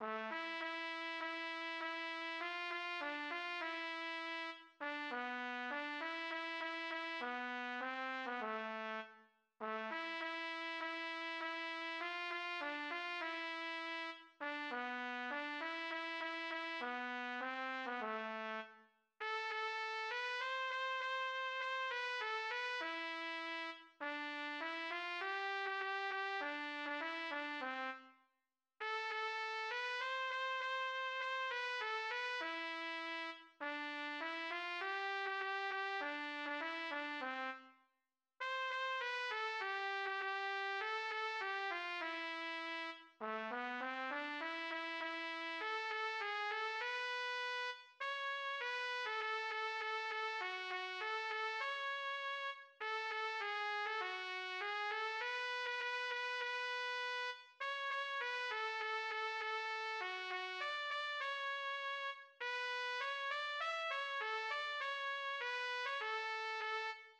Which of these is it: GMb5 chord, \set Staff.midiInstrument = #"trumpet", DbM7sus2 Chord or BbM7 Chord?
\set Staff.midiInstrument = #"trumpet"